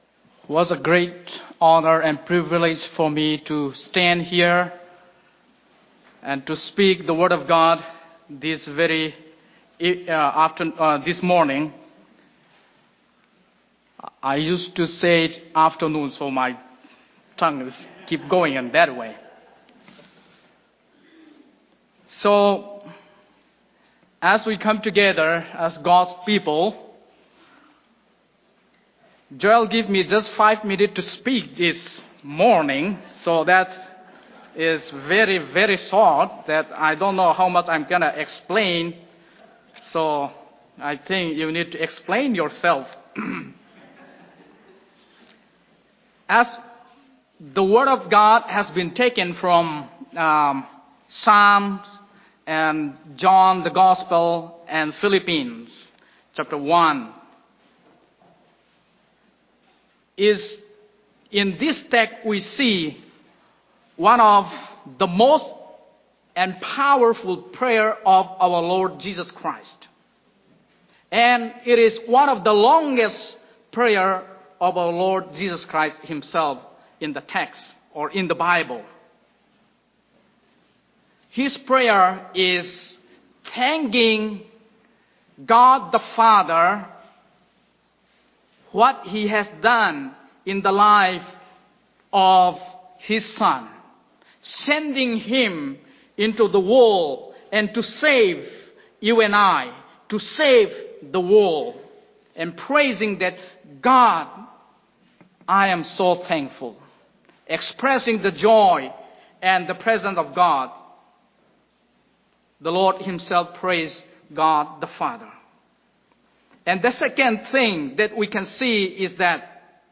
A combined communion service of First Karen Baptist Church, Regina Chin Church, and First Baptist Regina Church